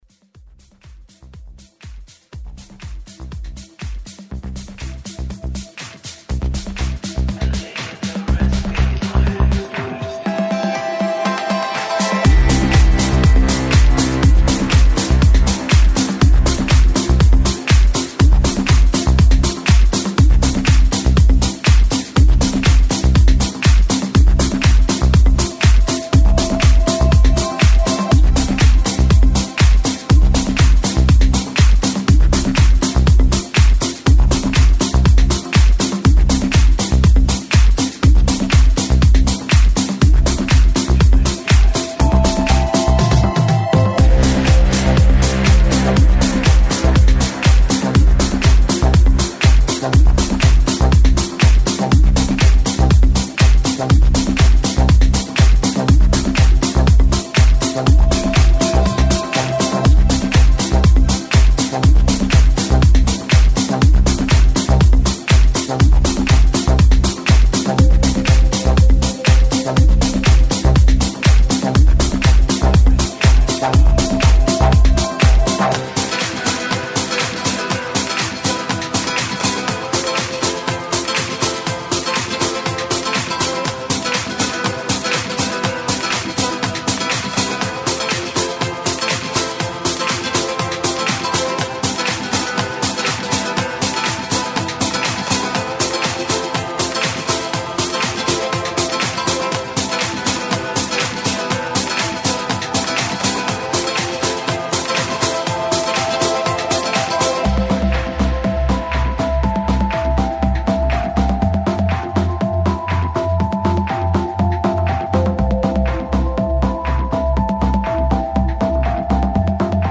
[ TECHNO | HOUSE ]